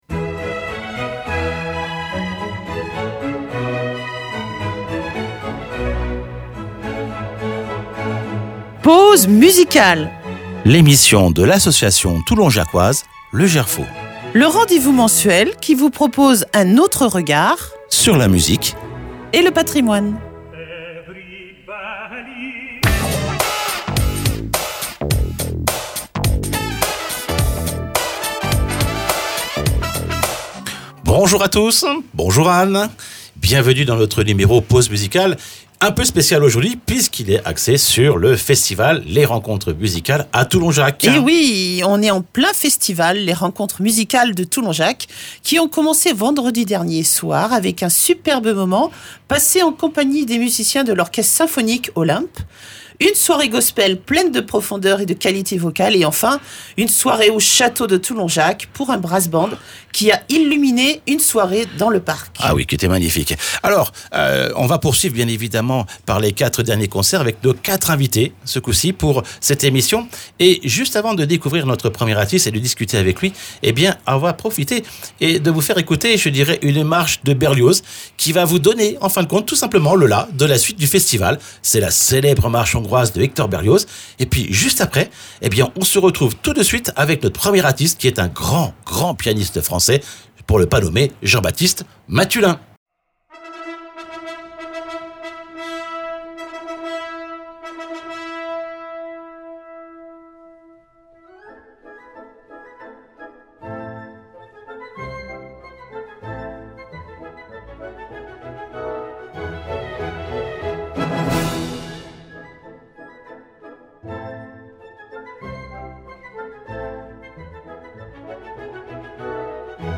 Une émission en compagnie d’artistes, présents au festival organisé par l’association Le Gerfaut.